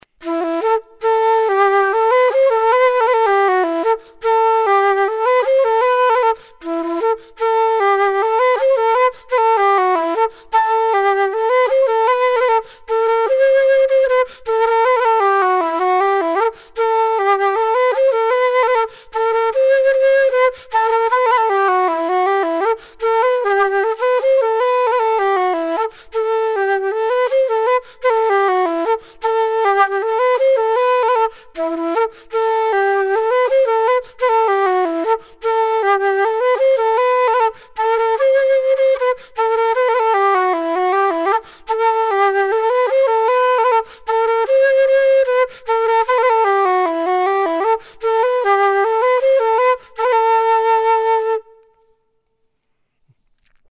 M&E en redwood (palissandre du Mexique, ou cocobolo)
Le son est proche de la flûte en blackwood.